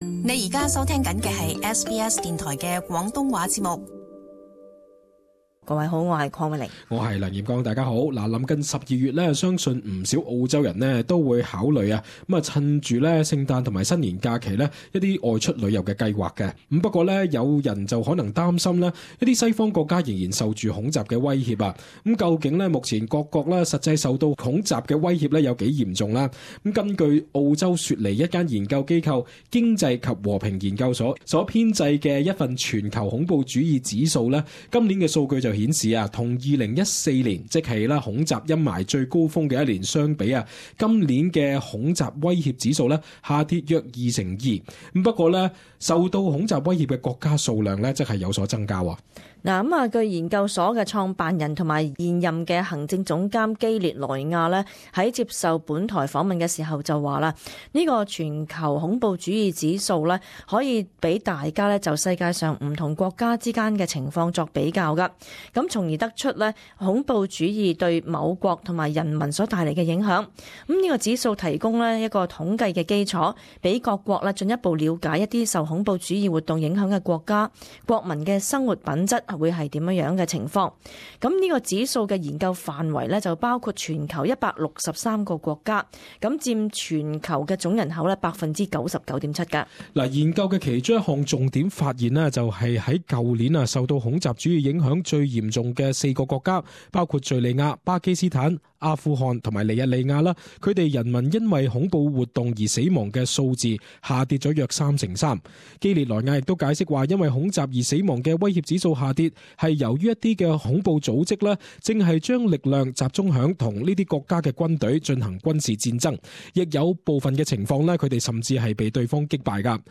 【時事報導】最新全球恐怖主義指數出爐